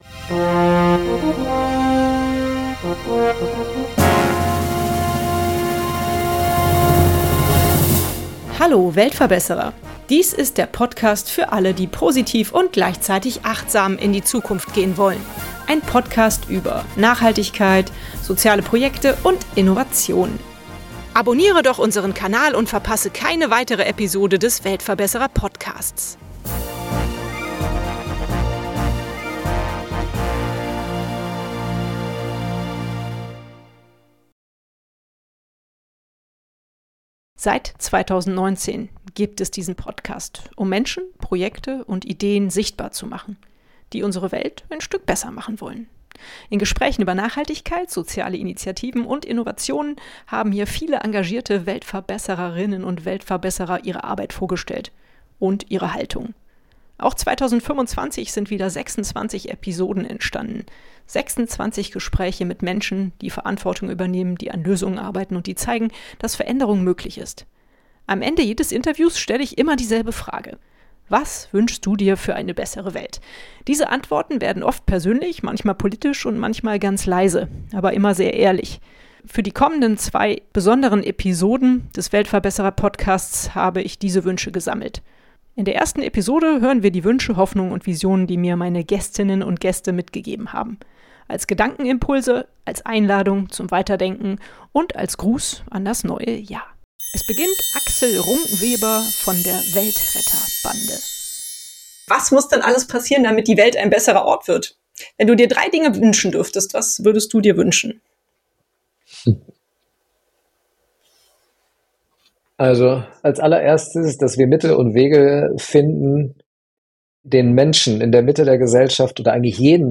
In dieser besonderen Episode habe ich diese Antworten gesammelt. Stimmen aus dem Podcastjahr 2025 – ehrlich, nachdenklich, hoffnungsvoll.
Diese Folge ist kein klassisches Interview, sondern eine Collage aus Gedanken, Hoffnungen und Visionen.